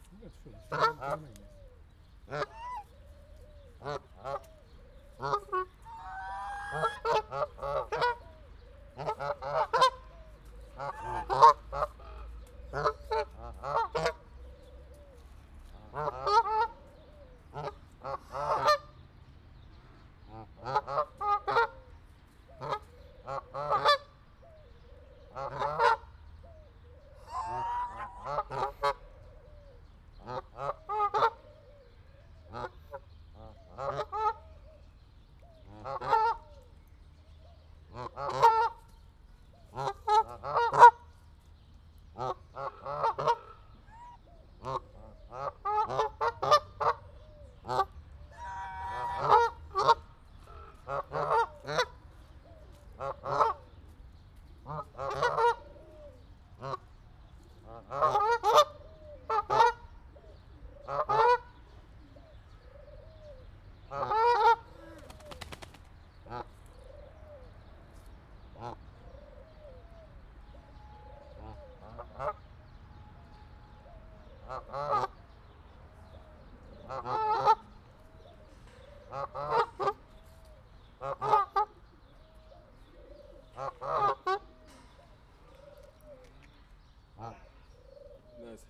erosebbhanguakanadailud_egeszenhalkakinaihattyulud0128.WAV